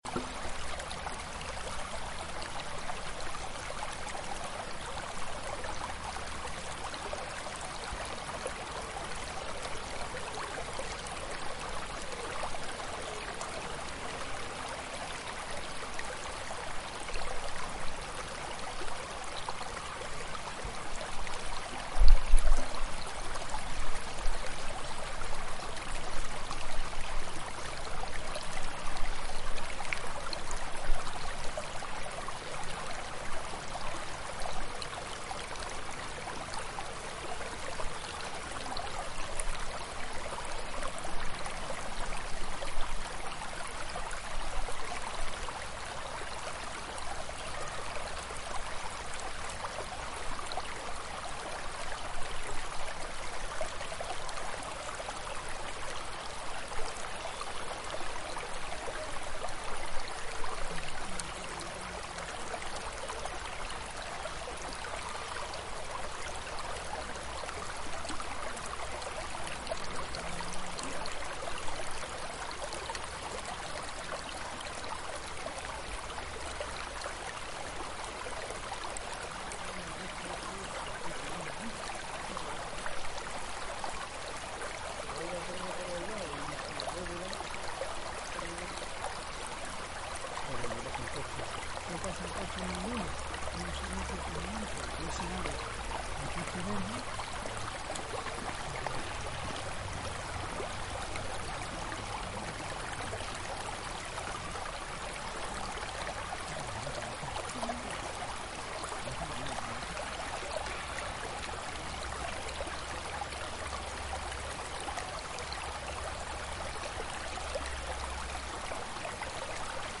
Sonds of the Alhambra (1). Recorded by iesalbayzin